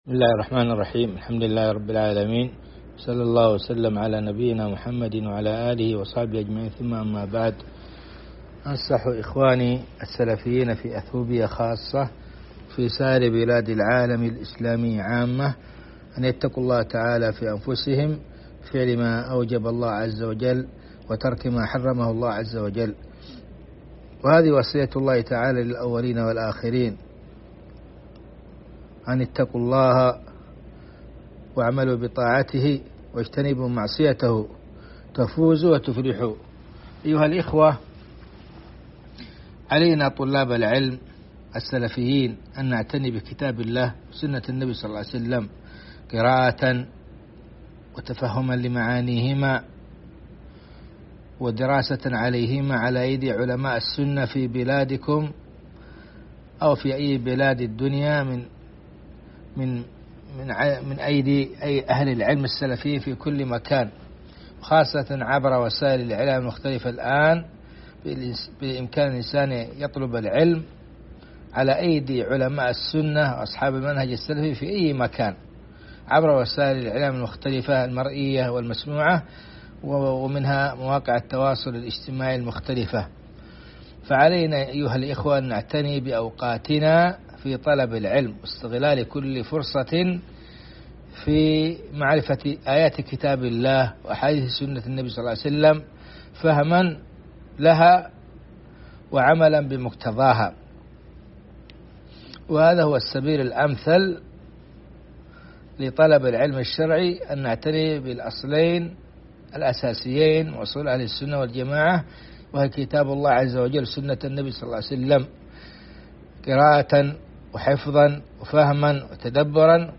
مواعظ ورقائق